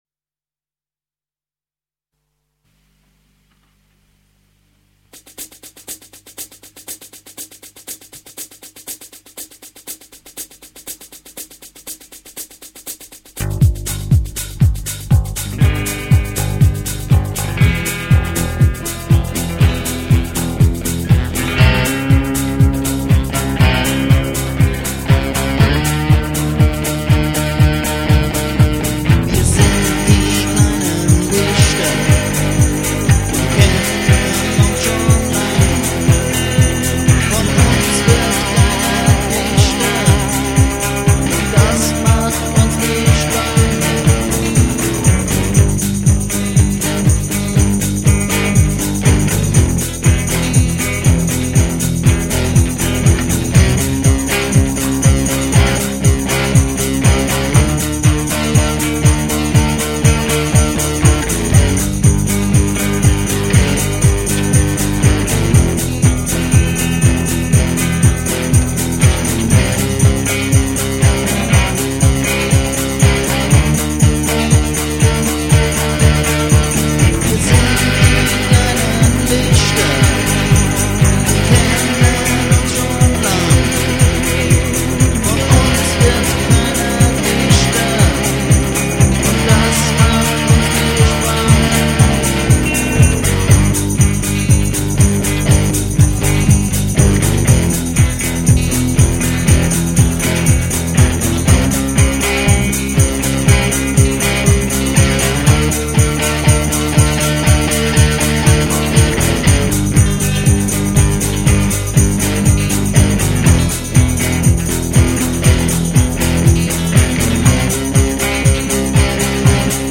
Recorded at the Roof-Top-Chamber, Bad Ems 16.12.2000
Karaoke-Version